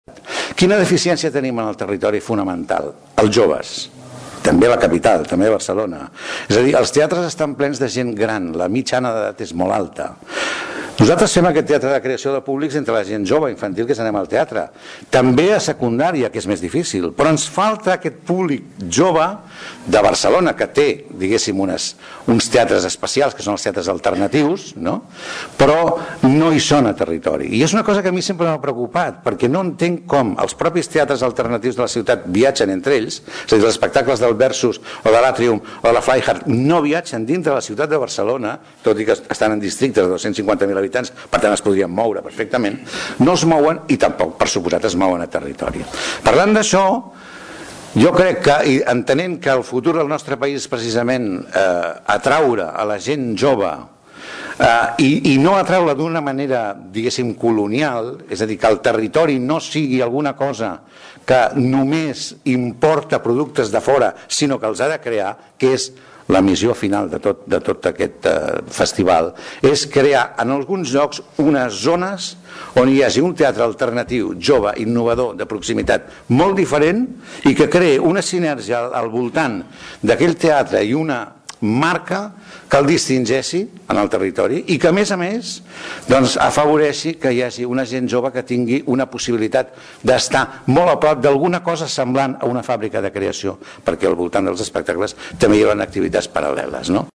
En una roda de premsa, on van participar el diputat de Cultura Juanjo Puigcorbé i l’alcalde de Tordera, Joan Carles Garcia, entre d’altres es va presentar la programació d’espectacles i els eixos que defineixen el Festival Zero, que neix amb la voluntat de convertir-se en un cita anual. Juanjo Puigcorbé explicava que un dels objectius de la programació és acostar els joves al teatre.